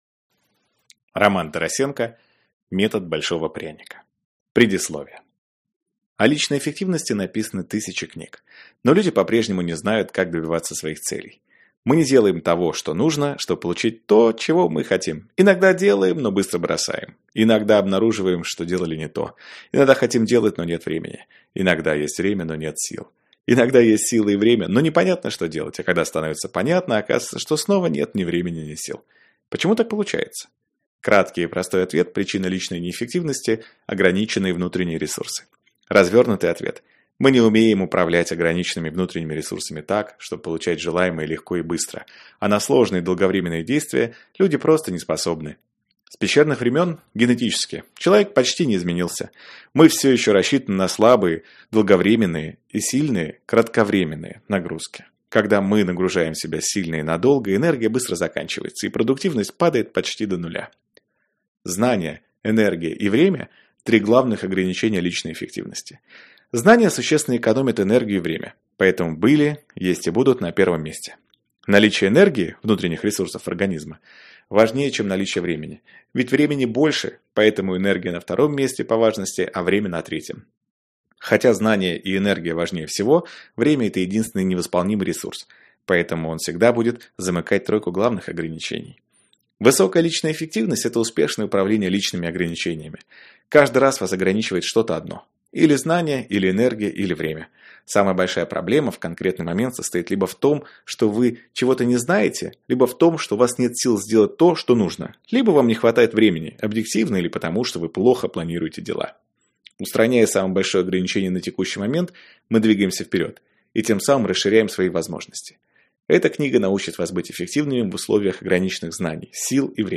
Аудиокнига Метод большого пряника | Библиотека аудиокниг